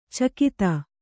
♪ cakita